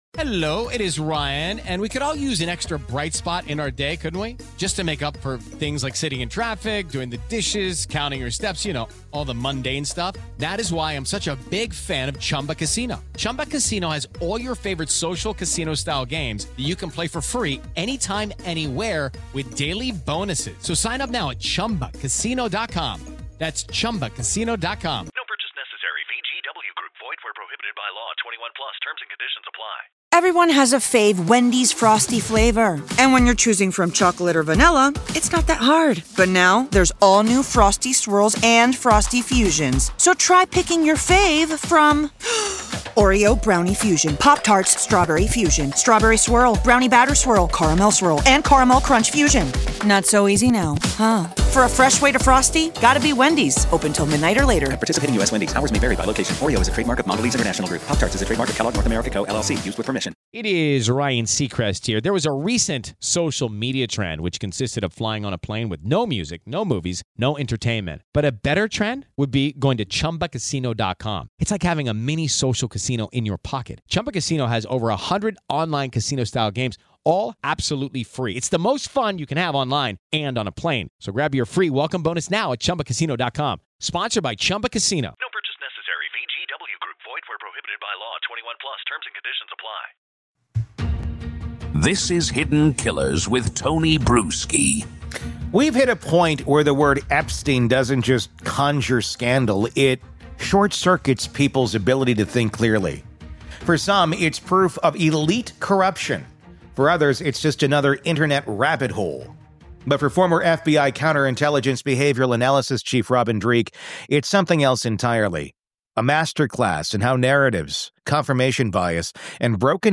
In this searing follow-up interview